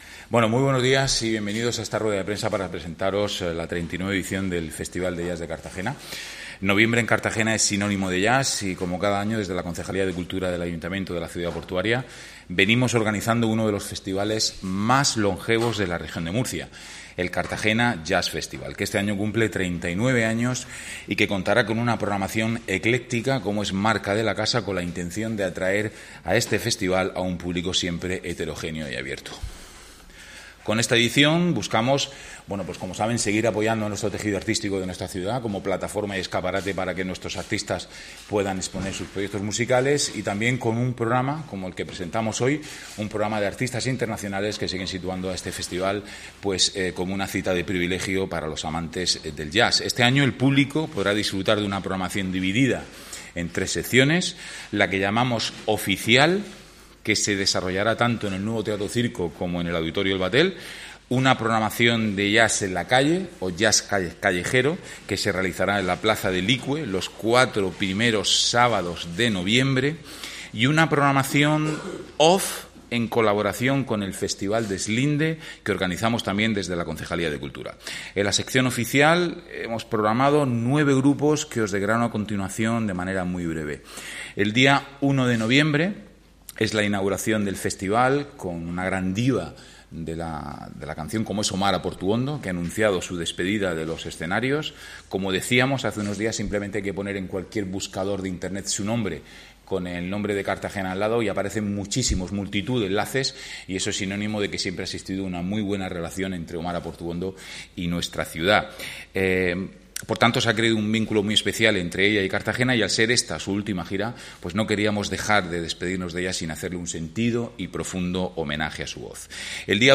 Audio: Rueda de Prensa Presentaci�n 39 Festival de Jazz (MP3 - 4,74 MB)